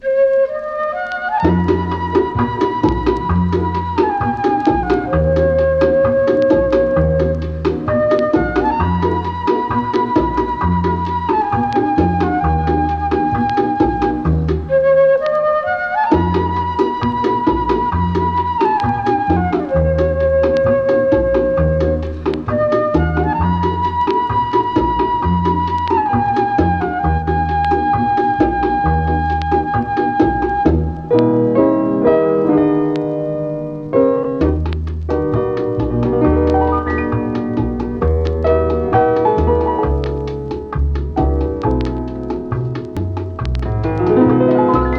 Jazz, Latin, Lounge　USA　12inchレコード　33rpm　Mono